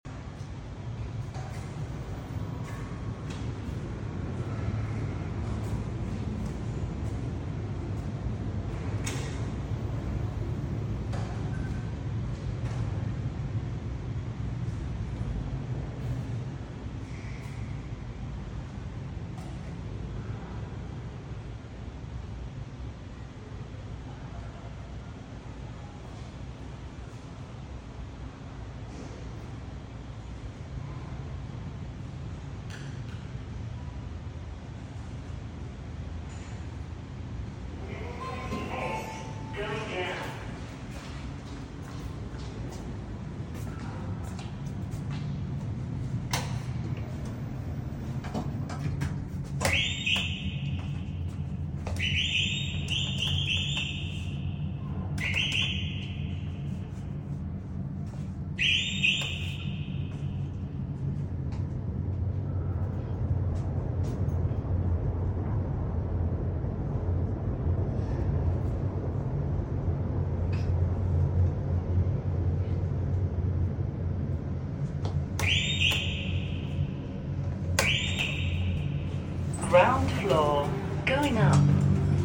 This alarm in this Kone sound effects free download
This alarm in this Kone Polaris elevator lift sounded like a bird chirping!